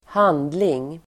Uttal: [²h'an:dling]